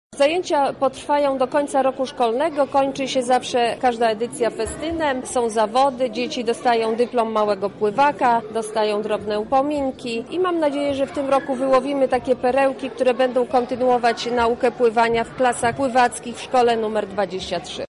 O projekcie mówi Marta Wcisło, radna Platformy Obywatelskiej i inicjatorka akcji: